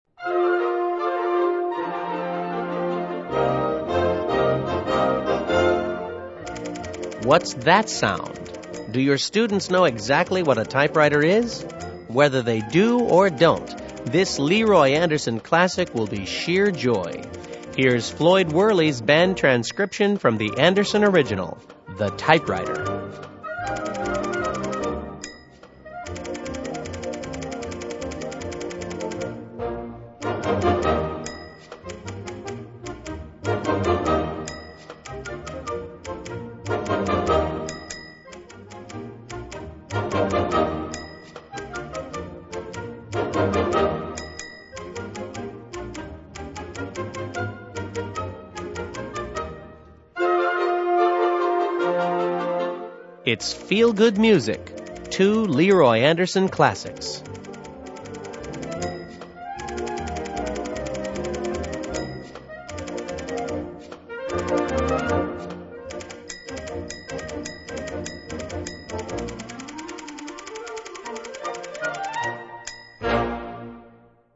Besetzung: Blasorchester
It's a zany sound but the enthusiasm is unmatchable.